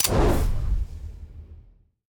select-flame-1.ogg